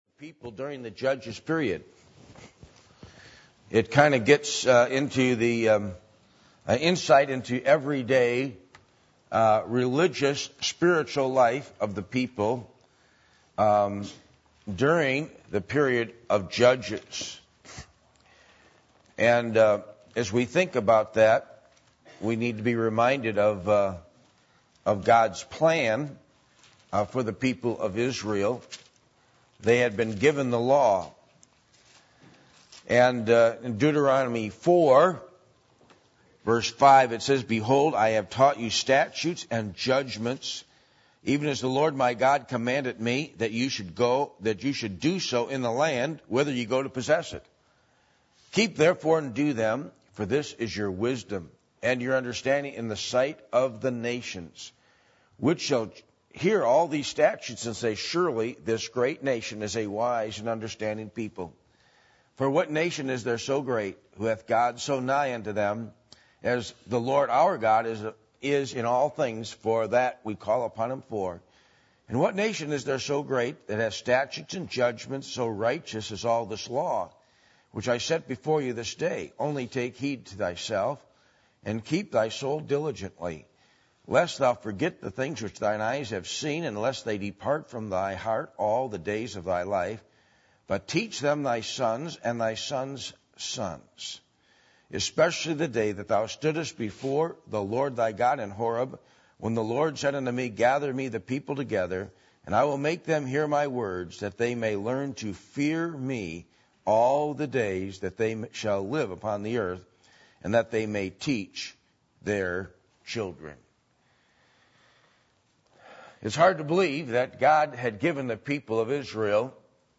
Passage: Judges 17:1-18:31 Service Type: Sunday Evening